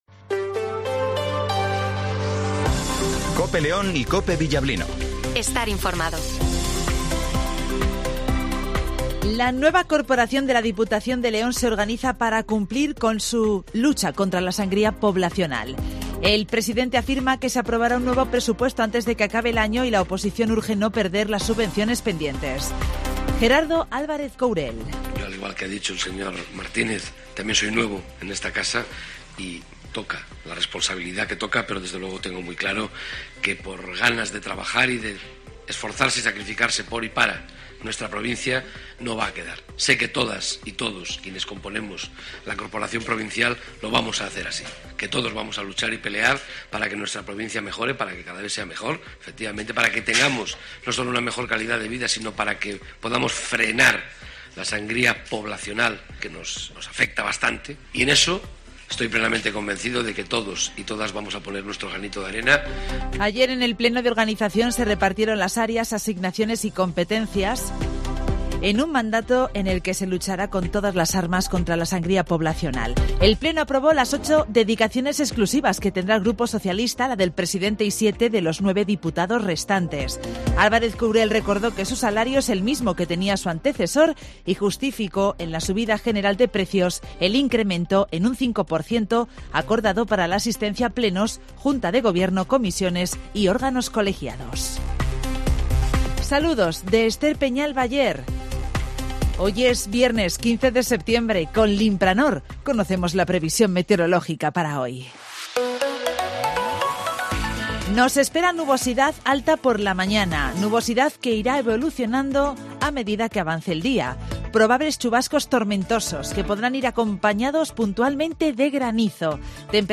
- Informativo Matinal 08:24 h